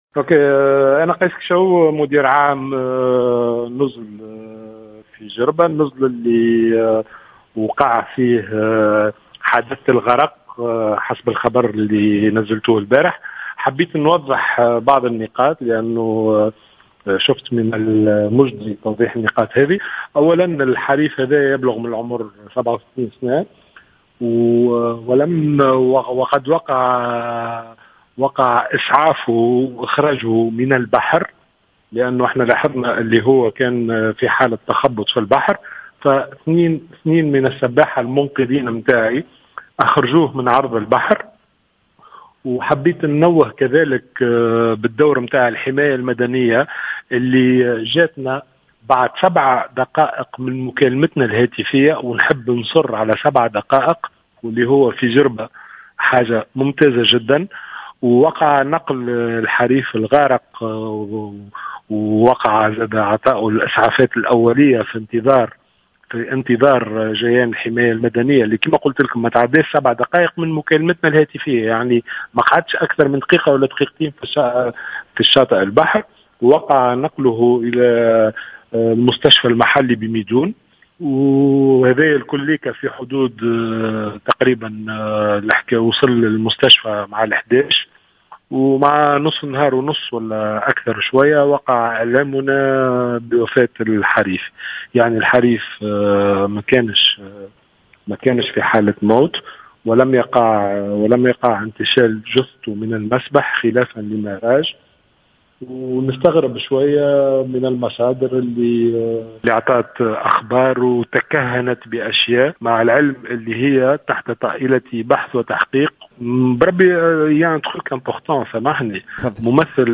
شهد أحد نزل جزيرة جربة أمس حادثة غرق سائح روسي حسب ما أفاد مصدر أمني مراسلة الجوهرة أف أم. و في اتصال هاتفي للجوْهرة أف أم اليوم تحدّث مدير عام النزل عن تفاصيل الحادث.